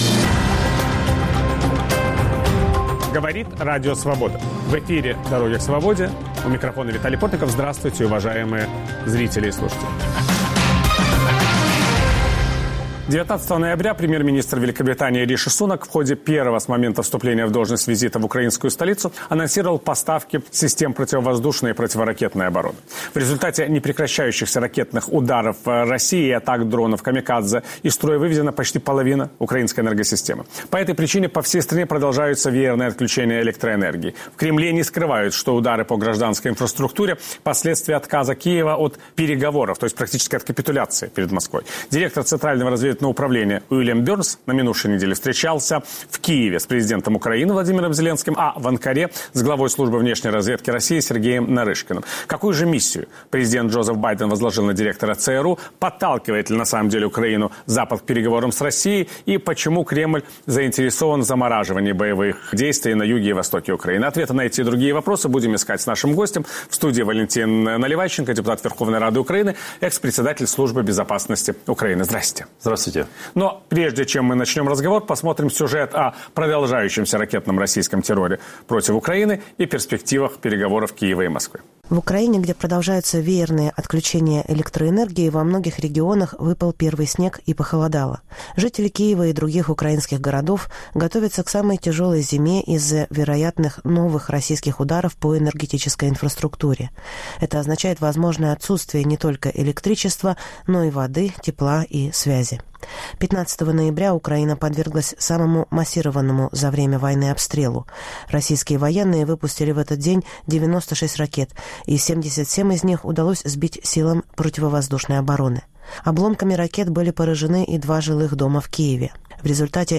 Приезд в украинскую столицу директора ЦРУ Вильяма Бернса после его встречи с Сергеем Нарышкиным в Анкаре. О скрытых аспектах поездок Бернса, о диалоге спецслужб Виталий Портников беседует с депутатом Верховной Рады, бывшим председателем Службы безопасности Украины Валентином Наливайченко.